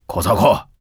c02_11肉铺敲门_2.wav